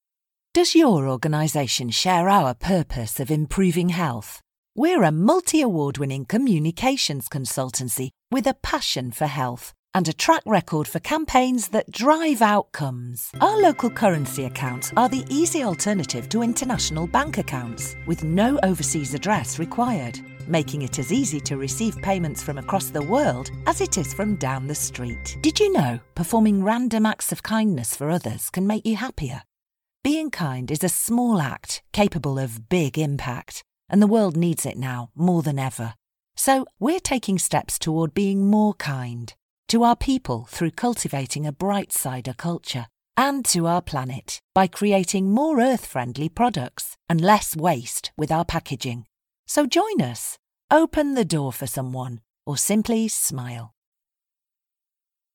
Stylish, educated English voice with warm tones & clear enunciation.
B2B Reel for website explainers and promos